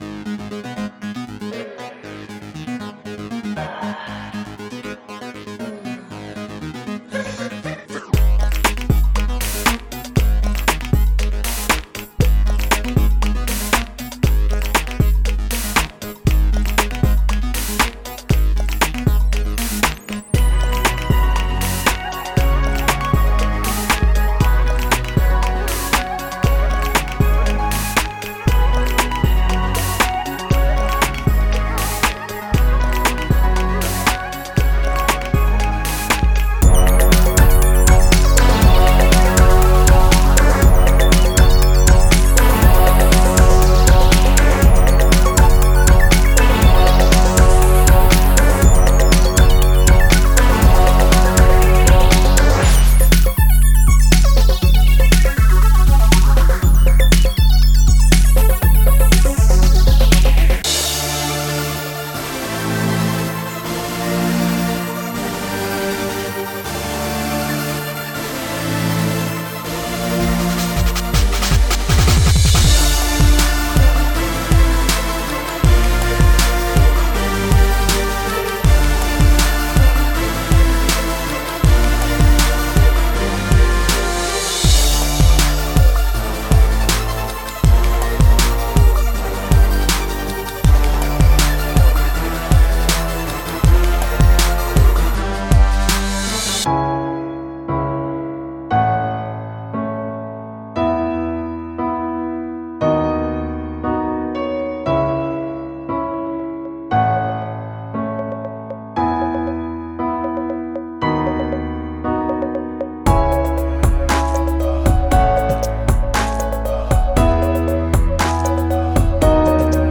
Construction Kits